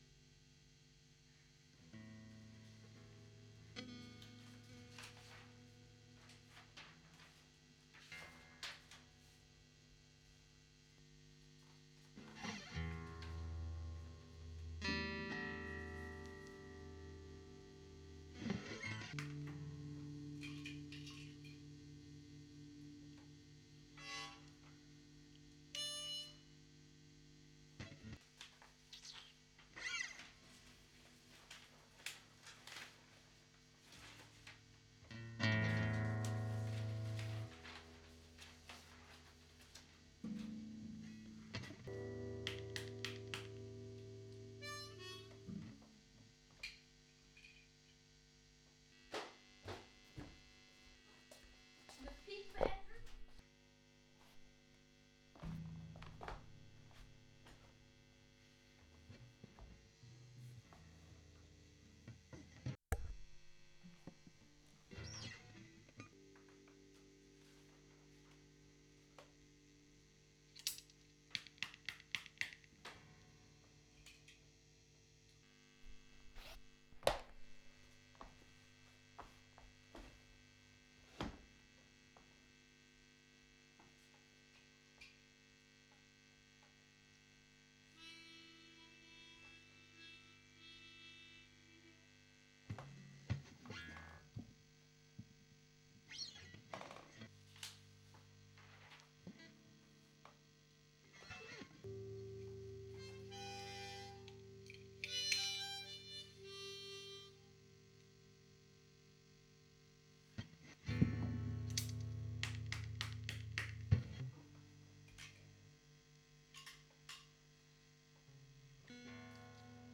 Studio-Aufnahmen Leerstellen